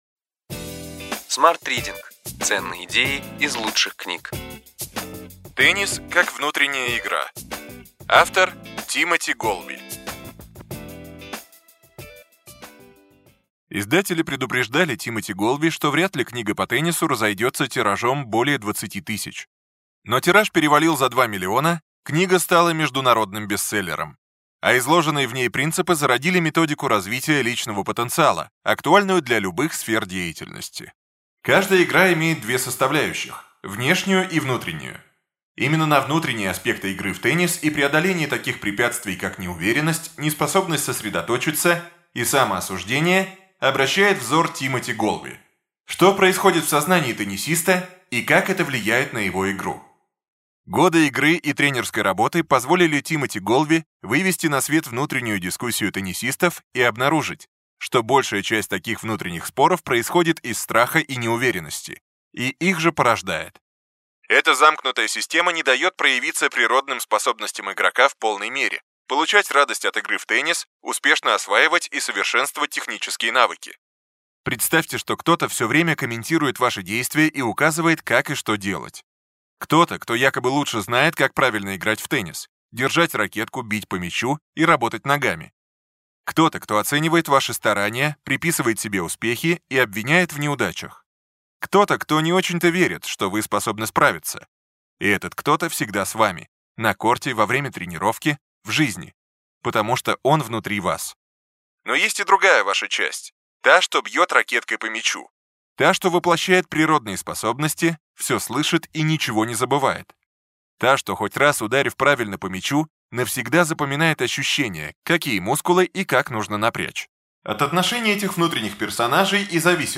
Аудиокнига Ключевые идеи книги: Теннис как внутренняя игра. Тимоти Голви | Библиотека аудиокниг